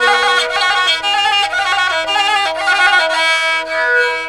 Index of /90_sSampleCDs/Sonic Foundry (Sony Creative Software) - World Pop/Stringed Instruments/Chinese